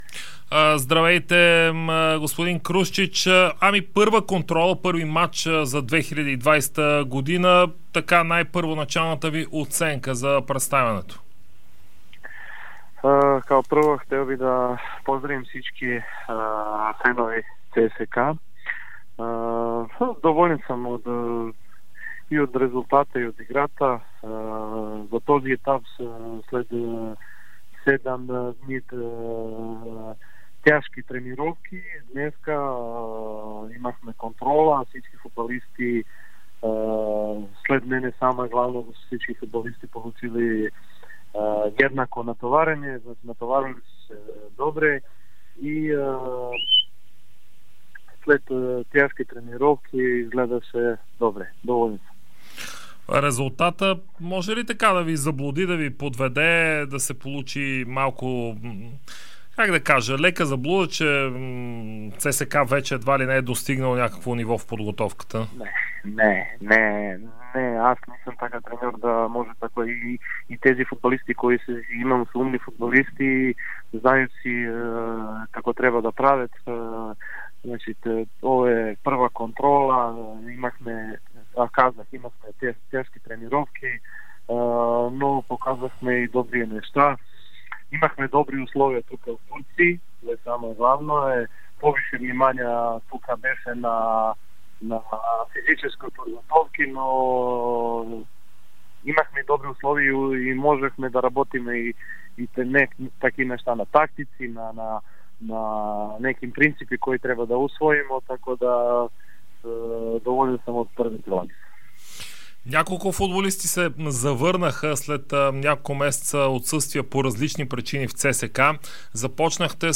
Старши треньорът на ЦСКА Милош Крушчич призна, че "червените" водят преговори с двама футболисти, които могат да бъдат привлечени в близките няколко дни. Той се включи в ефира на Дарик радио веднага след контролата на ЦСКА срещу Капсий, която "червените" спечелиха с 4:0.